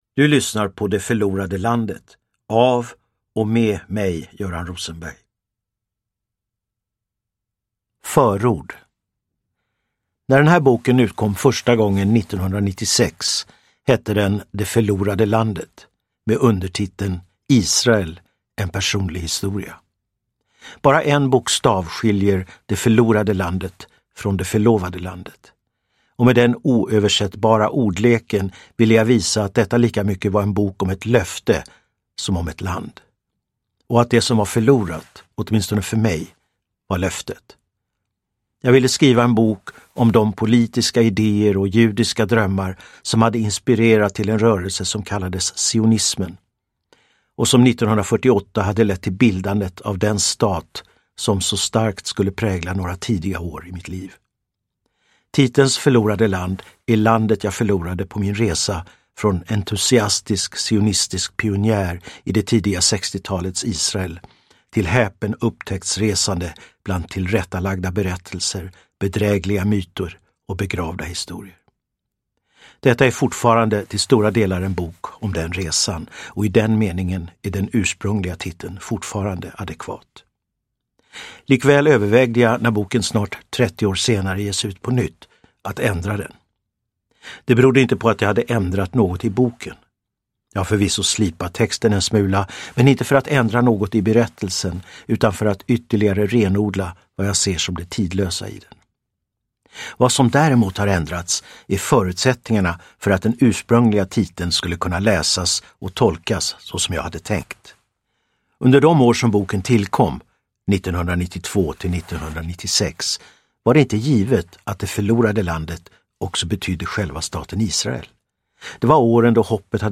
Uppläsare: Göran Rosenberg
• Ljudbok